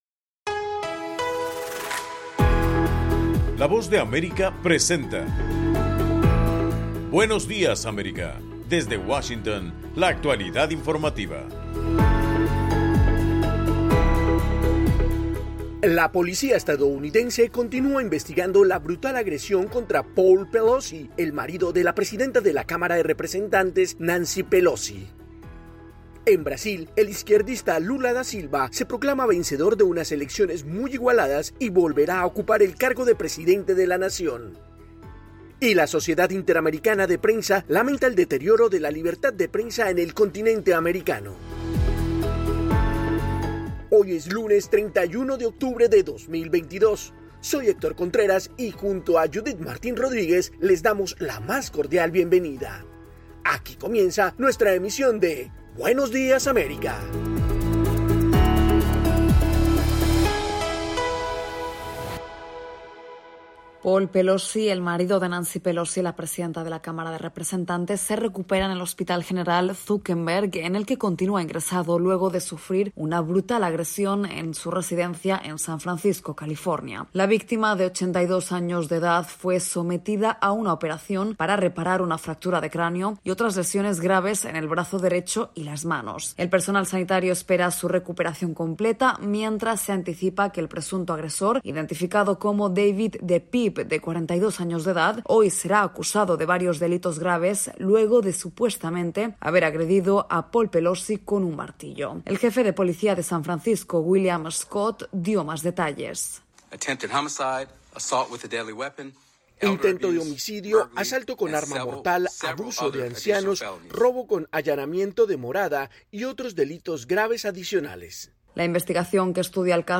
En el programa de hoy, 31 de octubre, la policía continúa investigando la brutal agresión contra Paul Pelosi, el marido de la presidenta de la Cámara de Representantes, Nancy Pelosi. Esta y otras noticias de Estados Unidos y América Latina en Buenos Días América, un programa de la Voz de América.